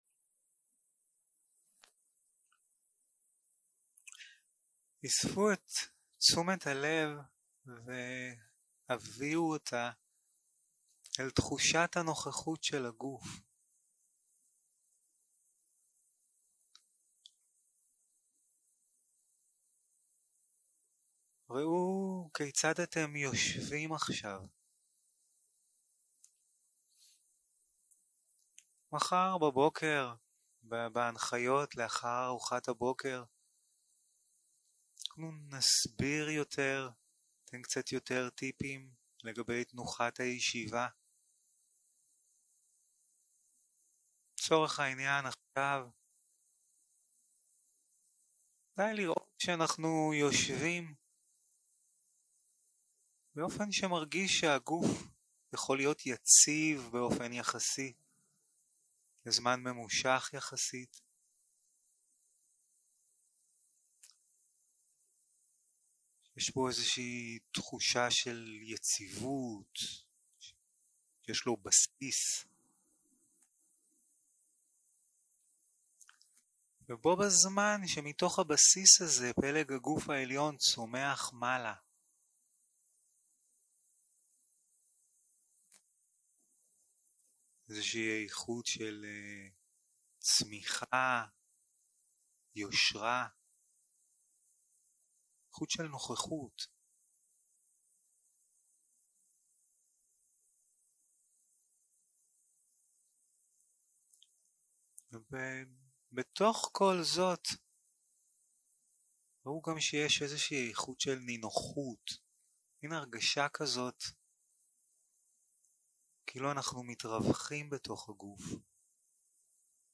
יום 1 - הקלטה 1 - ערב - מדיטציה מונחית
Dharma type: Guided meditation